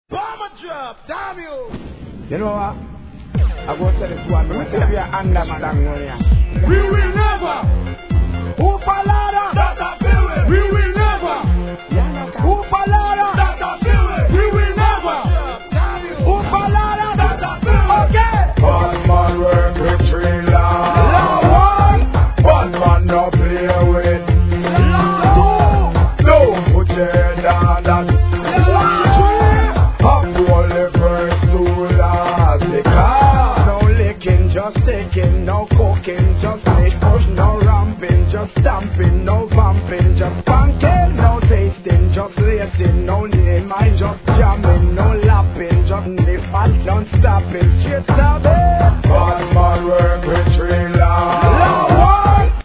REGGAE
超高速ダンスホール・トラック